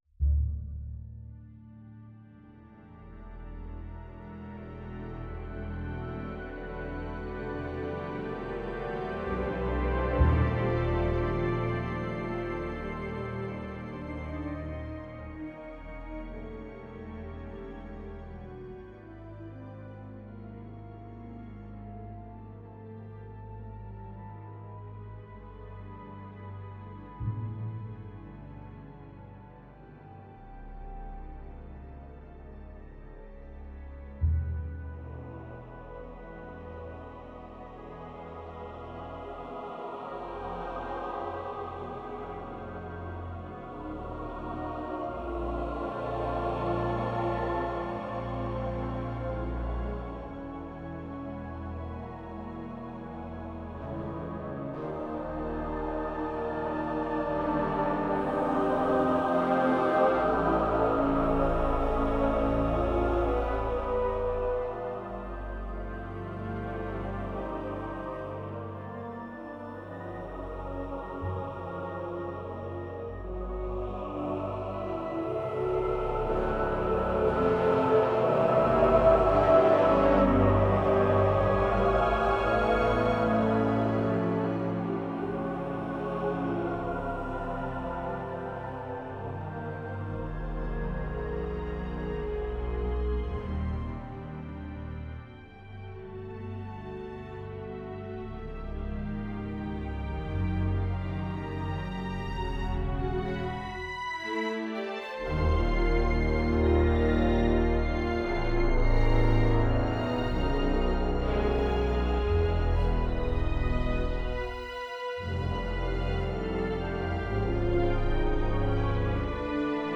two orchestras … three choirs